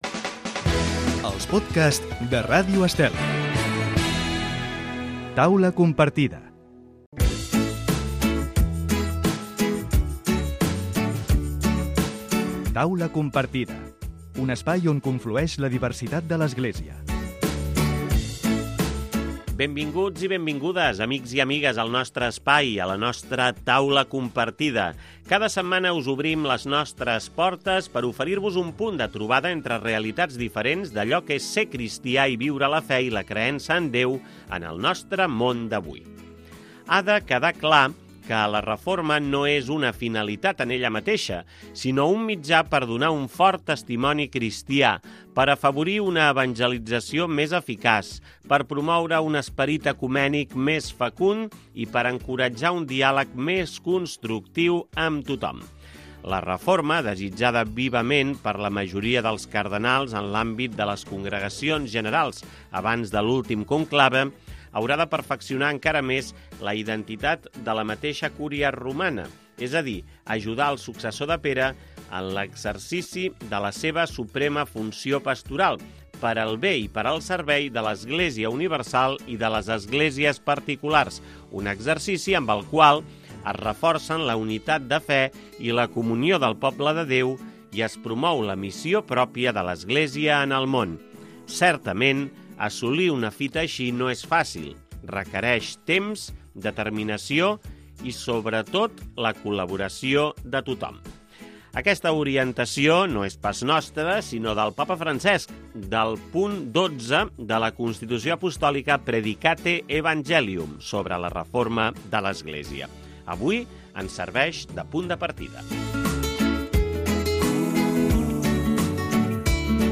Podcasts de contingut religiós i social en què dues o tres persones, procedents de diferents realitats de l'Església i de l'àmbit cristià, expliquen com viuen la fe i, en estar en una mateixa taula, comparteixen les seves vivències sempre des de l'enriquiment i la diversitat. L'espai comença amb una breu reflexió a la llum dels últims documents o missatges de l'Església i acaba amb una peça musical o cançó d'inspiració cristiana.